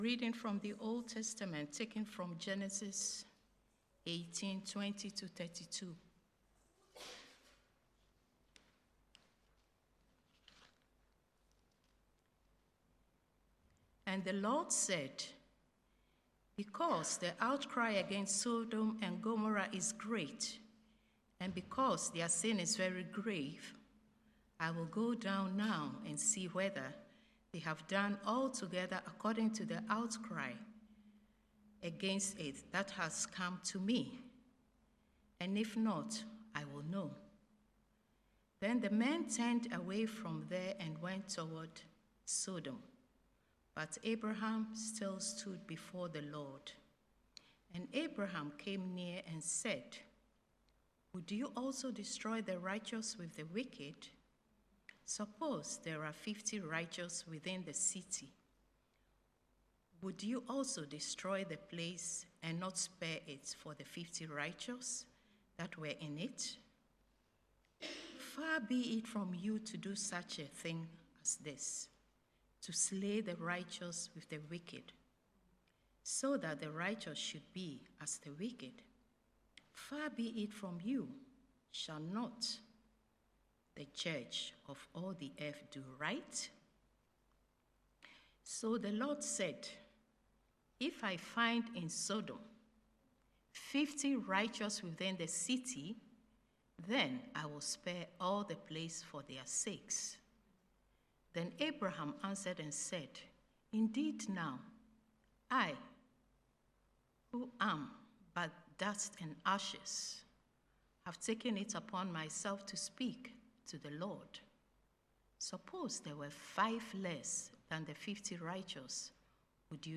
1 Affirmation of Faith 33:24 Play Pause 2d ago 33:24 Play Pause Play later Play later Lists Like Liked 33:24 Sermon – July 27, 2025 Lord, Teach us to Pray This sermon explores the disciples’ request to Jesus: “Lord, teach us to pray.”